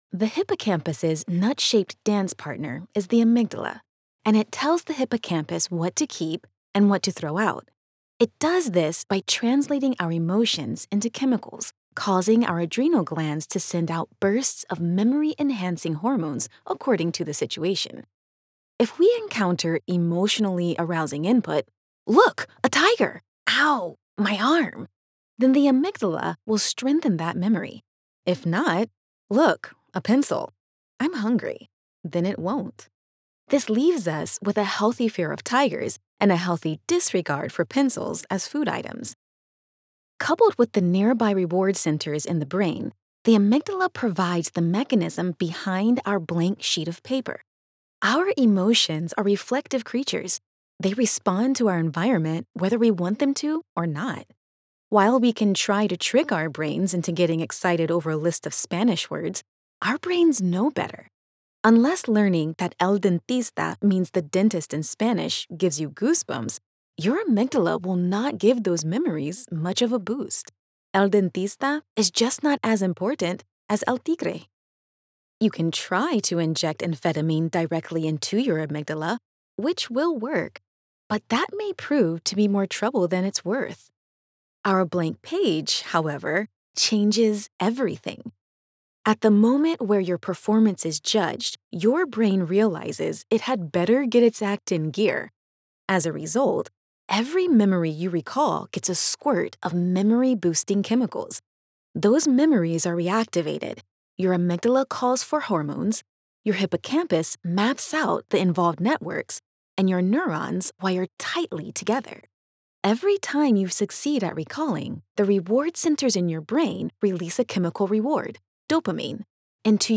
Authentic, friendly, female voice talent with quick turnaround and superb customer service
Nonfiction Audiobook-Science